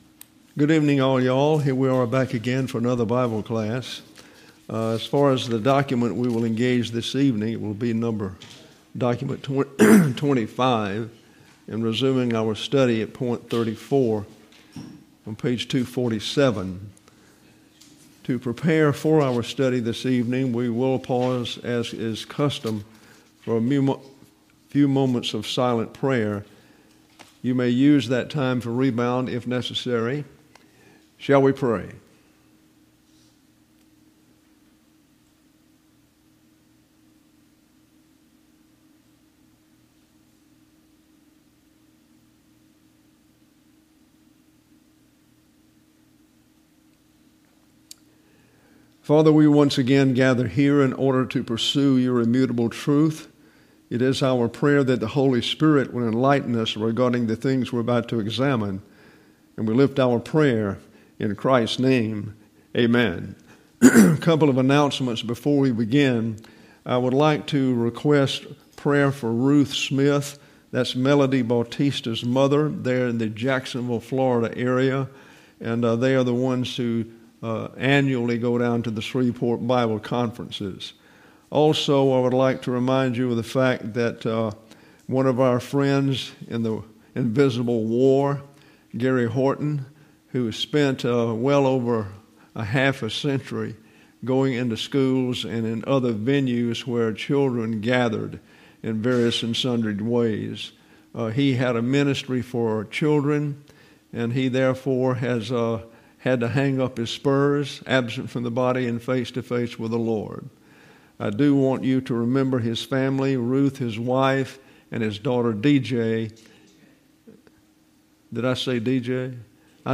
Jun 12, 2024Originally taught June 12, 2024
James Chapter Five: Lesson 87: Analysis of Verse 14: The Pastor-Teacher Is the Authority In The Local Church: Specific Prayer For The Dying Reversionist Who Has Rejected All Authority Including that of the Pastor-Teacher: Imperative Mood #52; Medical Treatment Cannot Help the Dying Reversionist; Expanded Translation of Verse 14;